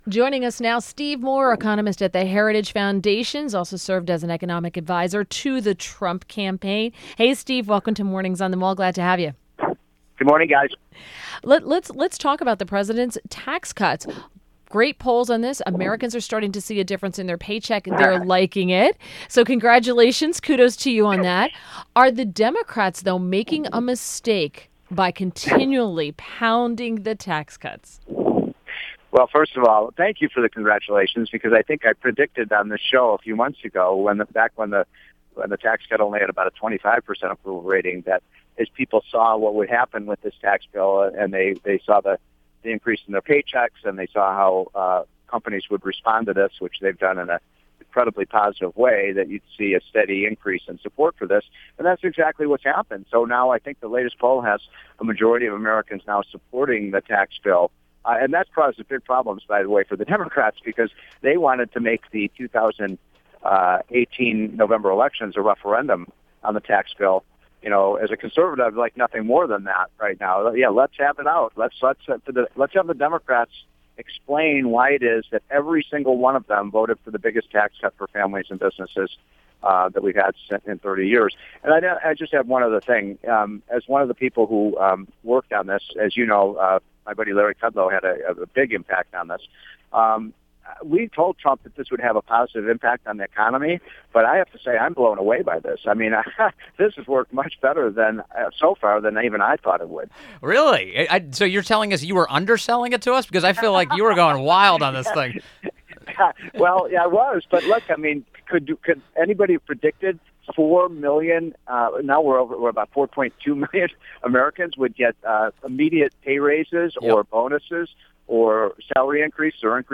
INTERVIEW - STEVE MOORE - Economist at The Heritage Foundation and served as an economic adviser to the Trump campaign – discussed fiscal conservatism and how Trumpnomics is different from Obamanomics.